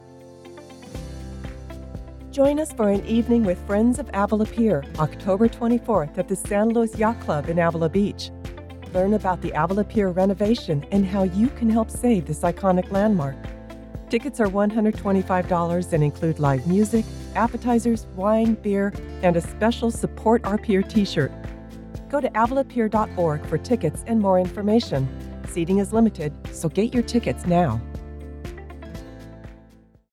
Female
English (North American)
Adult (30-50), Older Sound (50+)
I have a warm, friendly voice that can be conversational, soft, commanding, motherly, trustworthy, easy going and fun.
Television Spots